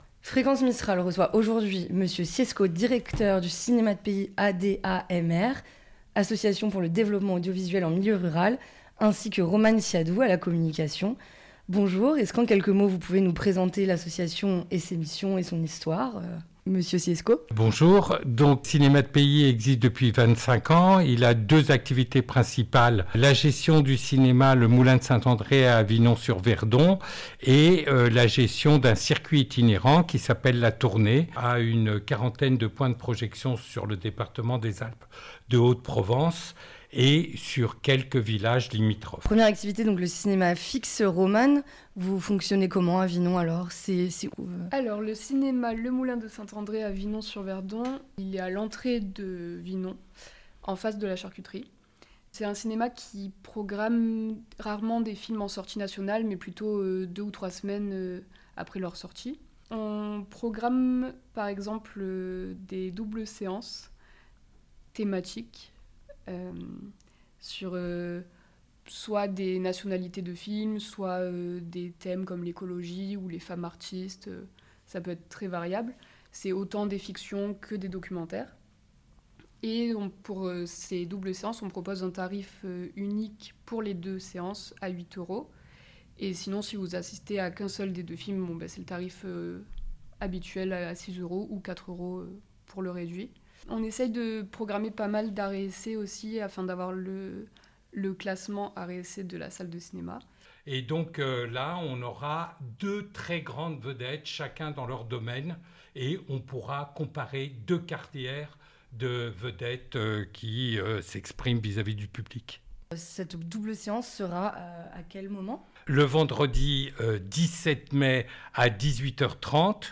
cine vinon.mp3 (23.57 Mo) Une partie de l'équipe Cinéma de Pays est venue en studio de Manosque pour nous présenter la programmation de cet été avec des événements en plein air, sur des thématiques variées mais toujours en lien avec le territoire et les publics. Cet échange a aussi été l'occasion de revenir sur le fonctionnement du cinéma en France, des difficultés rencontrées par les associations culturelles en milieu rural.